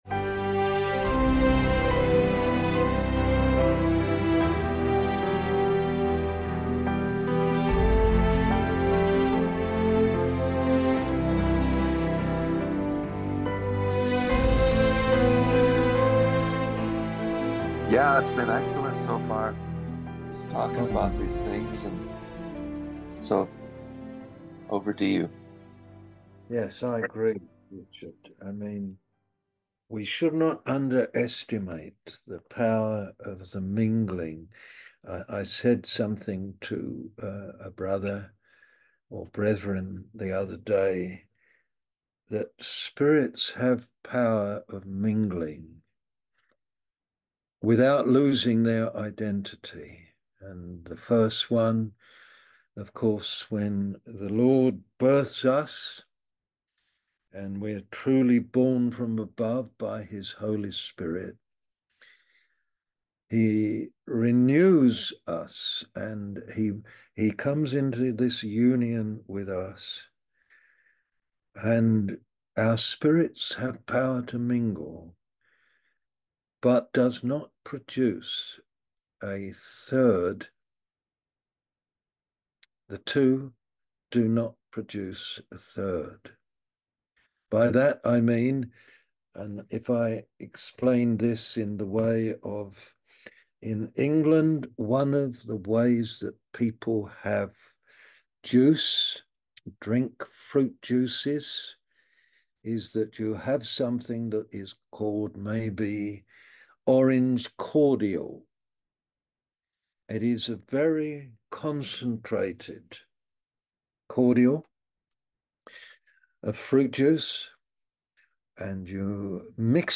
A message from the series "US Mens Meetings."